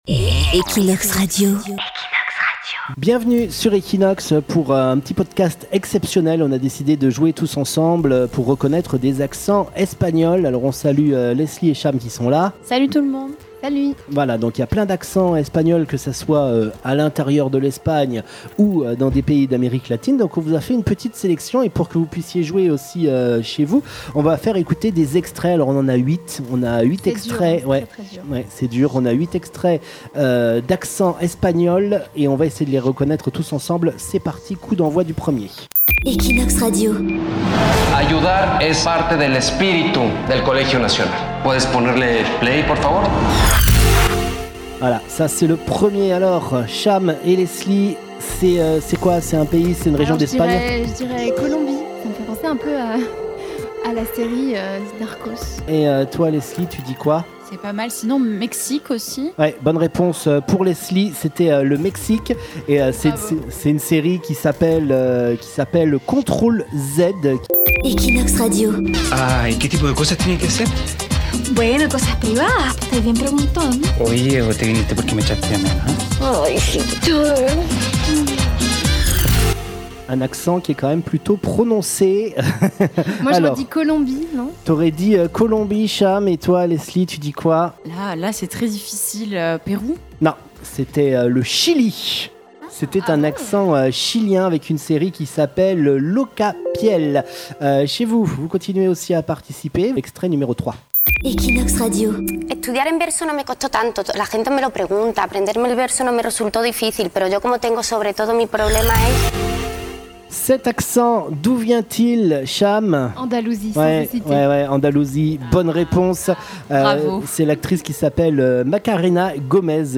Saurez-vous reconnaître ces accents espagnols ?
405 millions de personnes parlent la langue espagnole dans 30 pays différents. D’un endroit à l’autre, la prononciation n’est pas exactement la même. Saurez-vous reconnaître ces différents accents ?